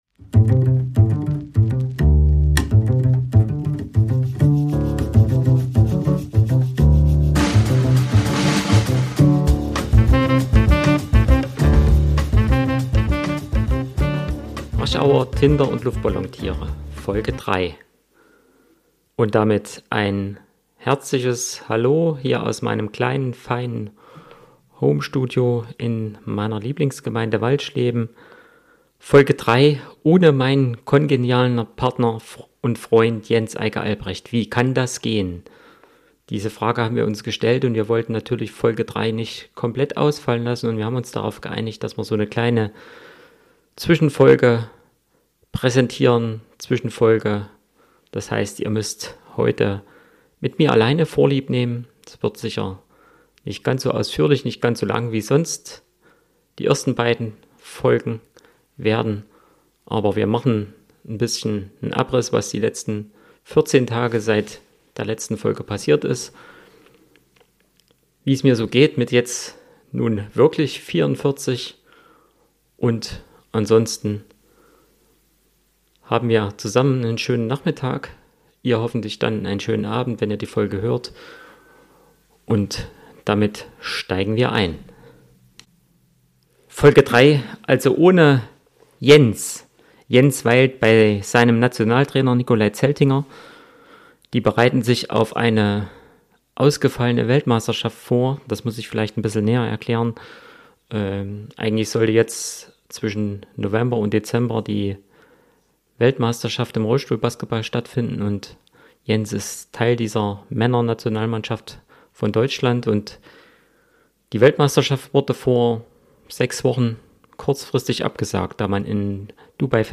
Aus dem Homestudio in Walschleben gibt es eines kleines Interview mit dem Bürgermeister selbst.